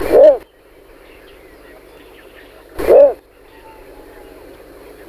Blongios nain, ixobrychus minutus
blongios.mp3